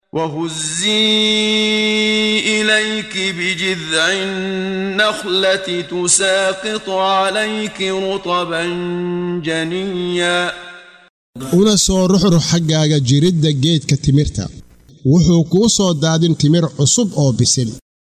Waa Akhrin Codeed Af Soomaali ah ee Macaanida Suuradda Maryam oo u kala Qaybsan Aayado ahaan ayna la Socoto Akhrinta Qaariga Sheekh Muxammad Siddiiq Al-Manshaawi.